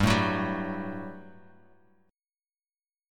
GM7sus2 chord